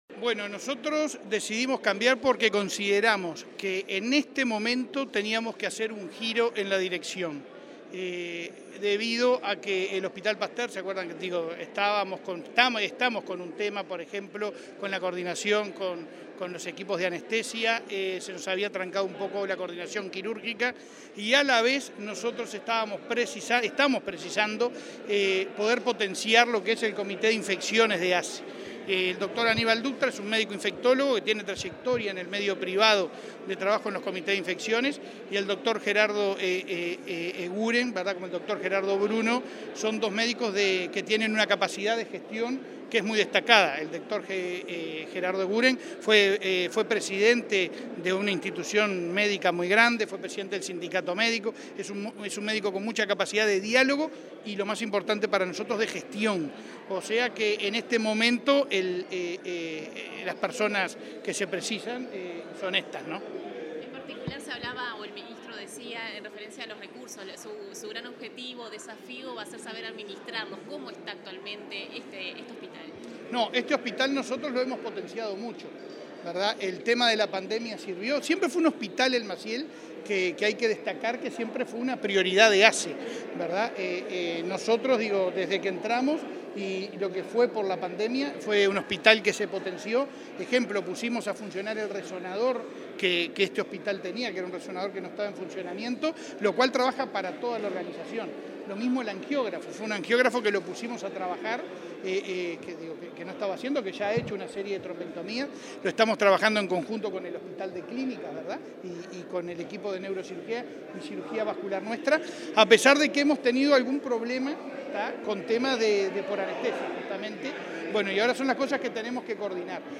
Declaraciones del presidente de ASSE, Leonardo Cipriani
El presidente de ASSE participó en el evento y luego dialogó con la prensa.